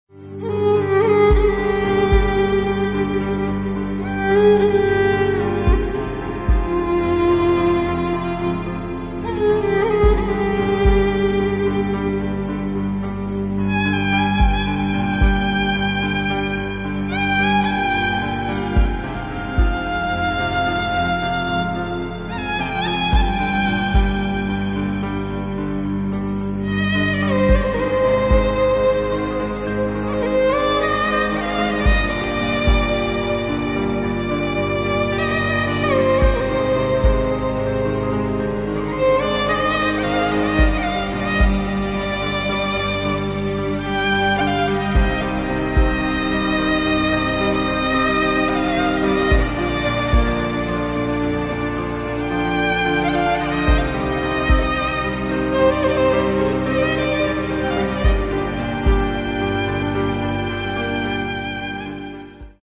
آهنگ زنگ بی کلام